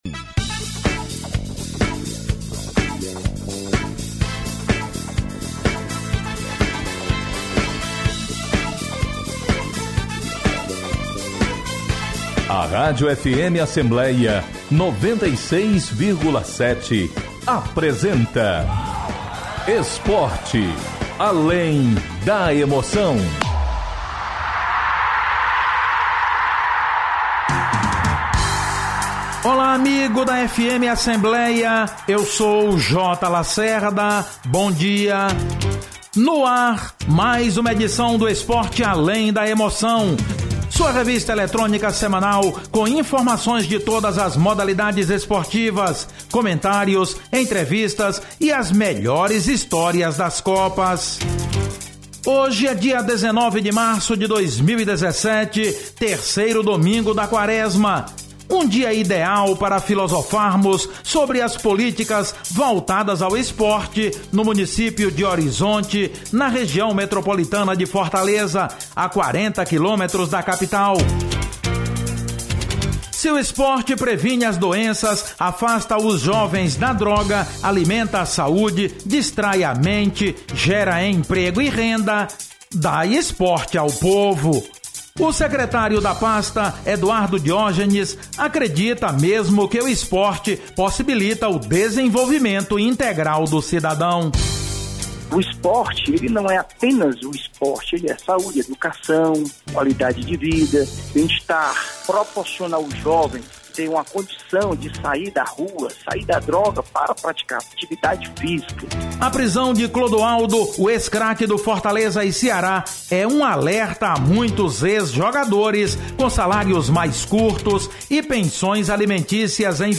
O programa Esporte Além da Emoção entrevista, neste domingo (19/03), às 9 horas, o secretário de Esportes de Horizonte, Eduardo Diógenes, sobre o dest...